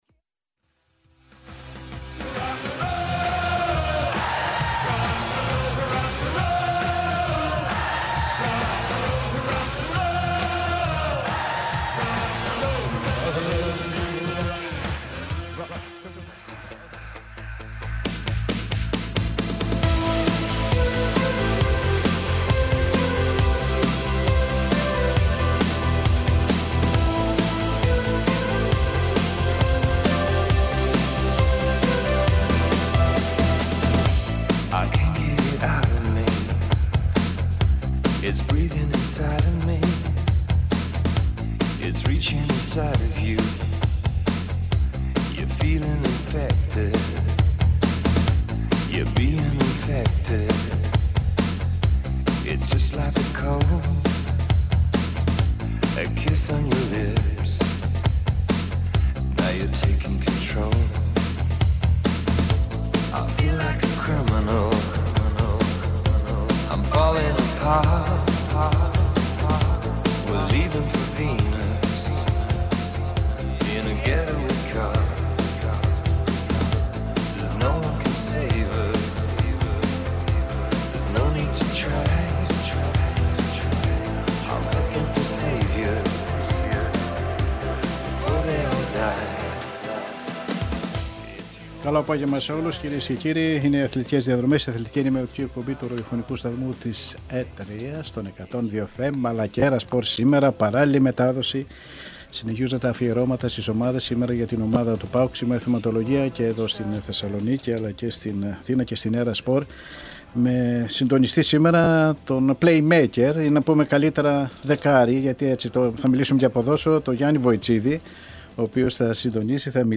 Συμμετείχαν οι συνάδελφοι δημοσιογράφοι